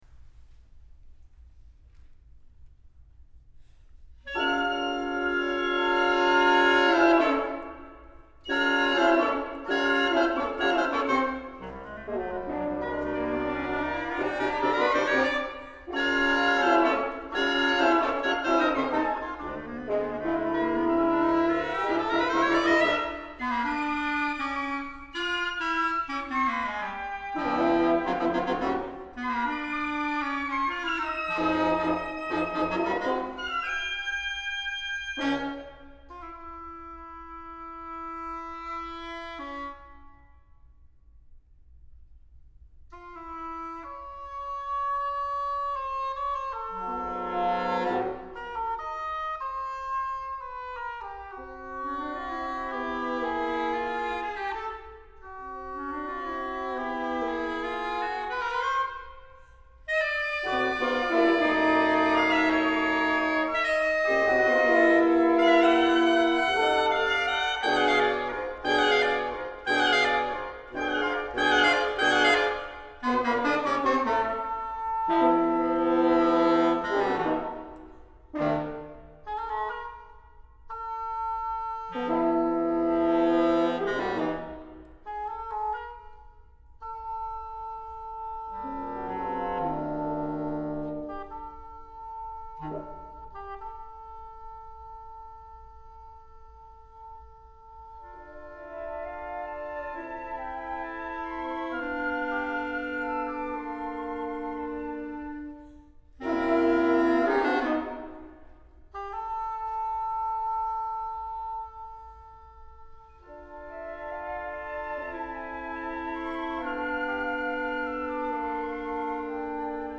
Partitions pour ensemble flexible, 8-voix.